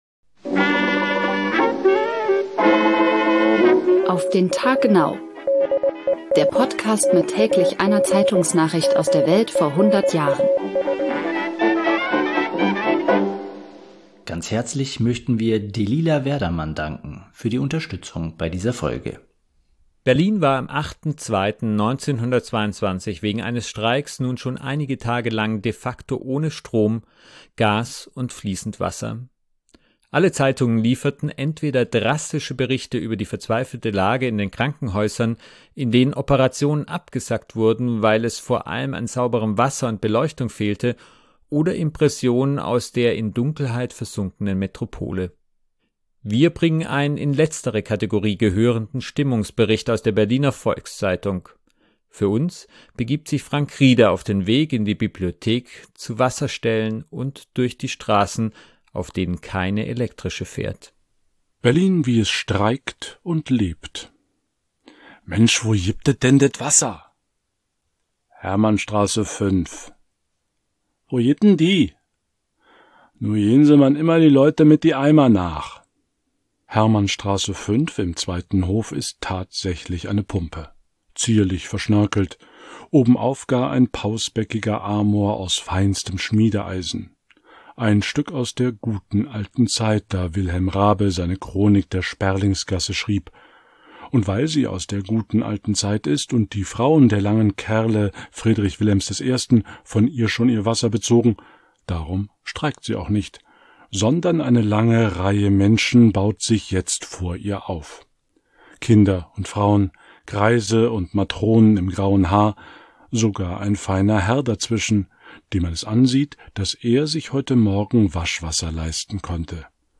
bringen einen in letztere Kategorie gehörenden Stimmungsbericht aus